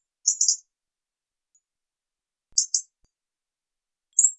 Erithacus rubecula - Robin - Pettirosso
DATE/TIME: 12/january/2003 (8 a.m.) - IDENTIFICATION AND BEHAVIOUR: the bird is perched on a hedgerow placed between an oak wood and cultivated fields. - POSITION: Poderone near Magliano in Toscana, LAT. N. 42 36' /LONG. E 11 16' - ALTITUDE: +130 m. - VOCALIZATION TYPE: territorial "tic" call. - SEX/AGE: unknown - COMMENT: striking difference in delivery rate if compared with Recording 1: this Robin is not performing a vocal duel with a neighbour. - MIC: (U)